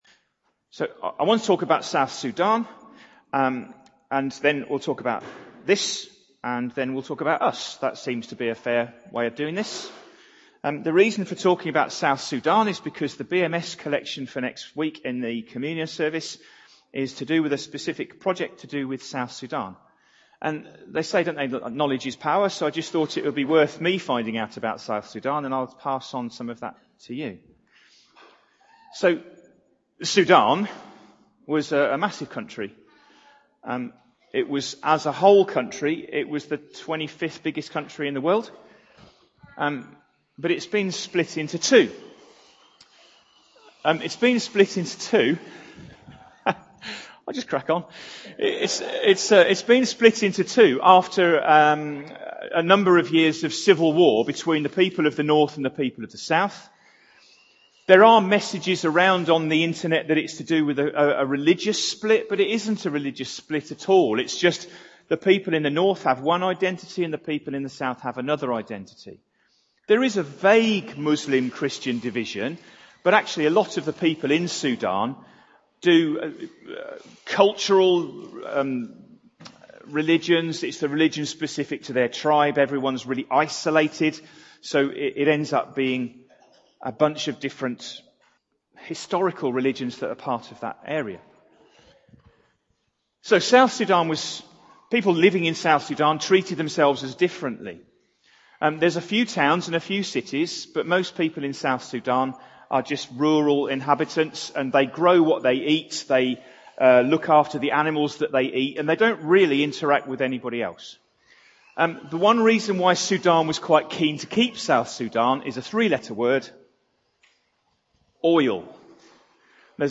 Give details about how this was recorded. Harvest Service